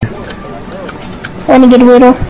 renegade-raider-made-with-Voicemod-technology-1-AudioTrimmer.com_.mp3